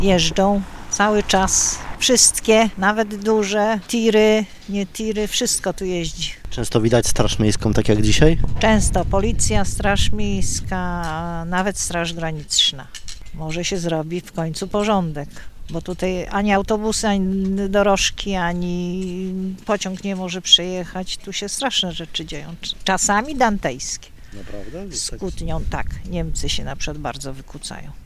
Dorożki, autobusy i pociągi nie mogą tędy przejechać – mówiła naszemu reporterowi jedna z mieszkanek Świnoujścia